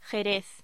Locución: Jerez